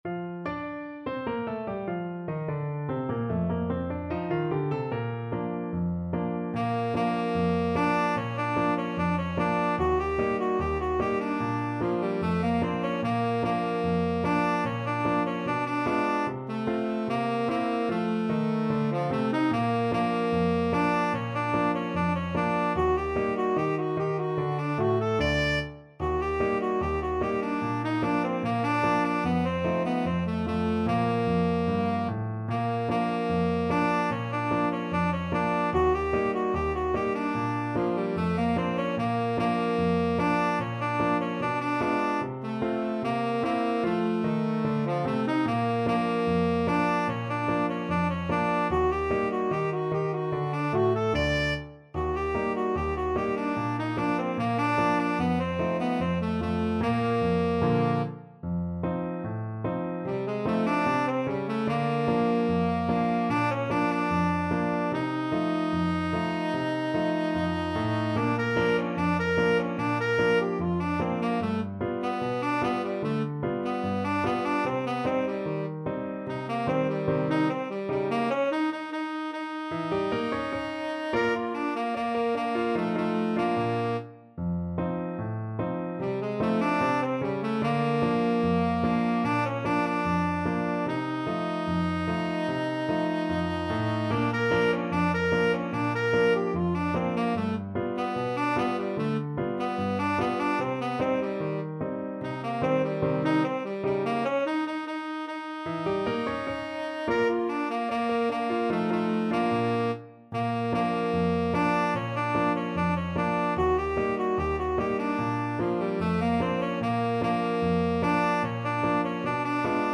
Free Sheet music for Alto-Tenor-Sax Duet
Alto SaxophoneTenor SaxophonePiano
Bb major (Sounding Pitch) (View more Bb major Music for Alto-Tenor-Sax Duet )
Not Fast = 74
2/4 (View more 2/4 Music)
Jazz (View more Jazz Alto-Tenor-Sax Duet Music)